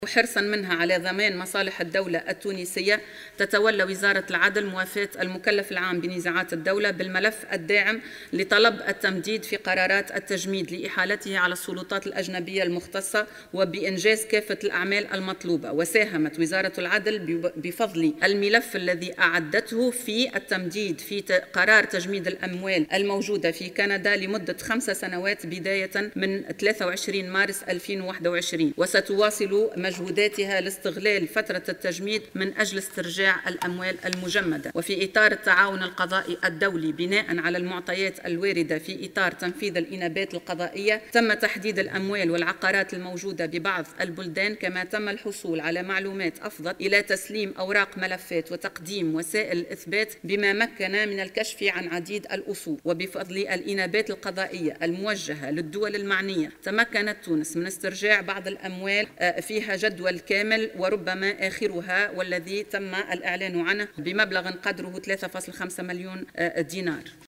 وأكدت بن سليمان في إجابتها على أسئلة النواب ، على هامش جلسة عامة بالبرلمان ، أن وزارة العدل وحرصا منها على ضمان مصالح الدولة ، تتولى موافاة المكلف العام بنزاعات الدولة بملفات التمديد المتعلقة بقرارات تجميد الأموال لإحالتها على السلطات الأجنبية المختصة .